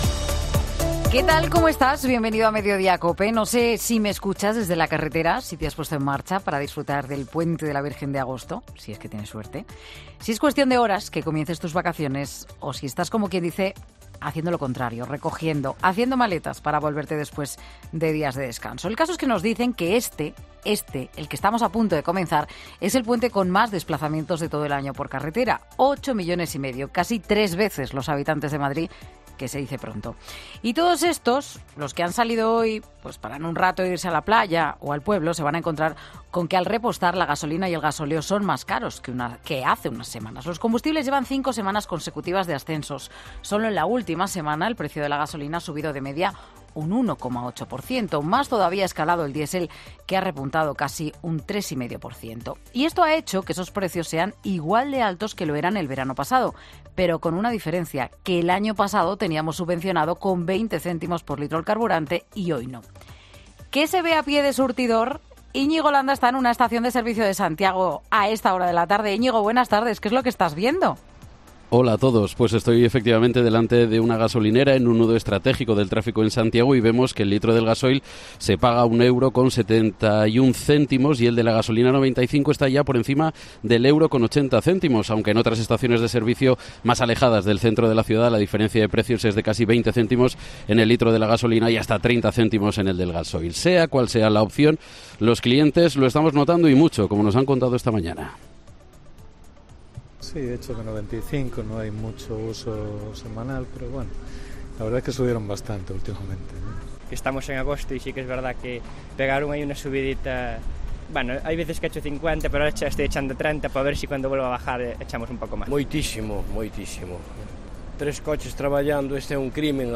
Las gasolineras son este fin de semana, por tanto, un punto de interés informativo y por ello Mediodía COPE, ha sondeado a los conductores en distintos puntos de España.